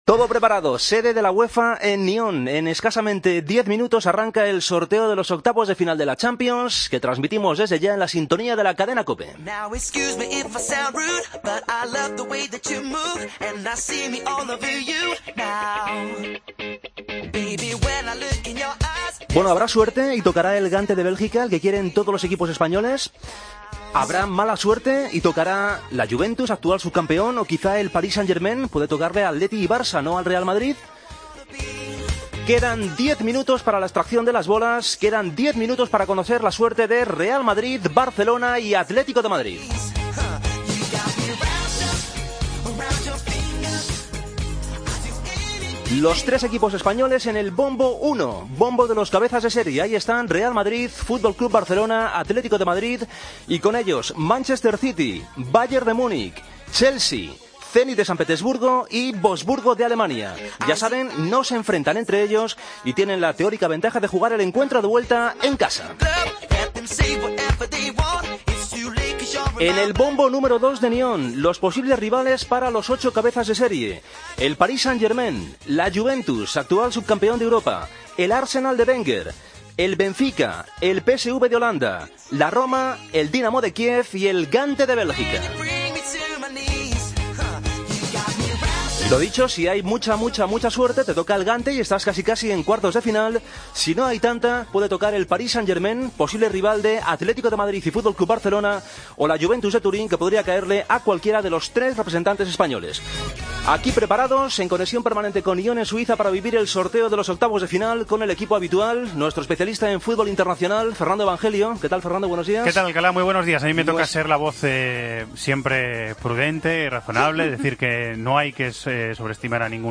Redacción digital Madrid - Publicado el 14 dic 2015, 12:37 - Actualizado 17 mar 2023, 17:51 1 min lectura Descargar Facebook Twitter Whatsapp Telegram Enviar por email Copiar enlace Desde las 12:00h, la redacción de Deportes COPE te ha contado en directo el sorteo de los octavos de final de la Champions League. PSV-Atlético, Roma - Real Madrid, Arsenal - Barcelona han sido los emparejamientos de los equipos españoles.